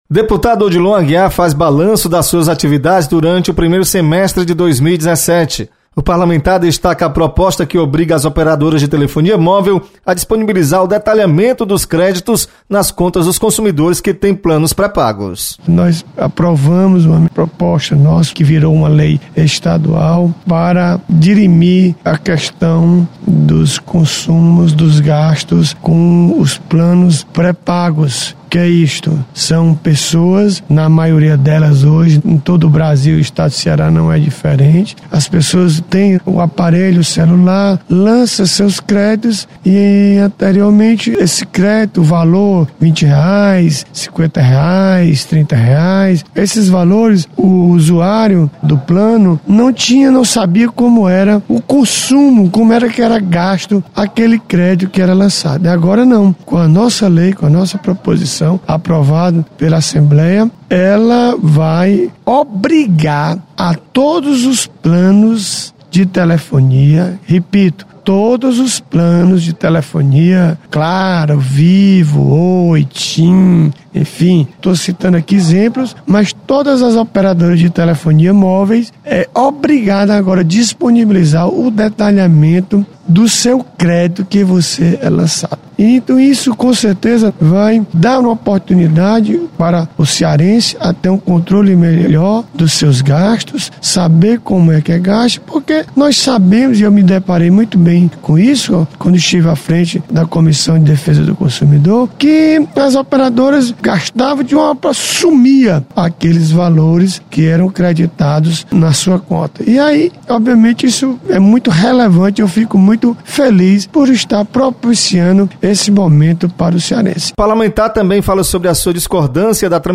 Deputado Odilon Aguiar destaca ações de seu mandato nos primeiro semestre de 2017. Repórter